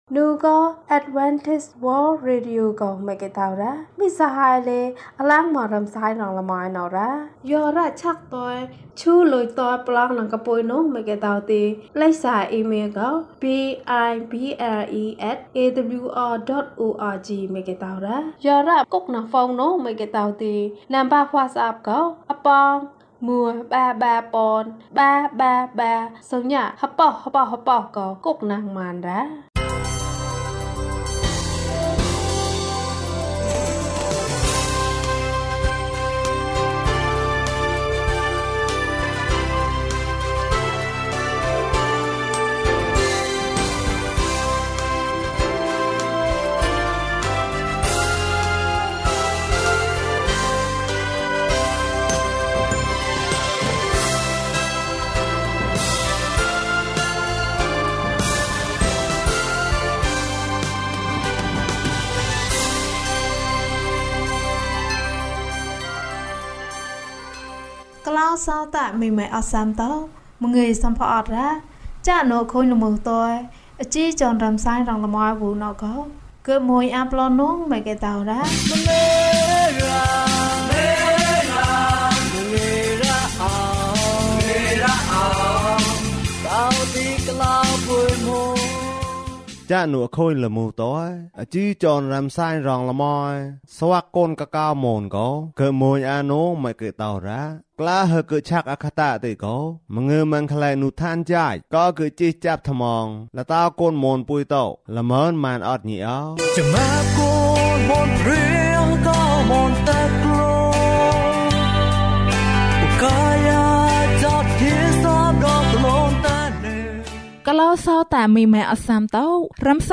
ဘုရားသခင်သည် ကျွန်ုပ်၏အရာအားလုံးဖြစ်သည်။၀၁ ကျန်းမာခြင်းအကြောင်းအရာ။ ဓမ္မသီချင်း။ တရားဒေသနာ။